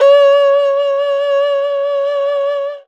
52-bi08-erhu-f-c#4.wav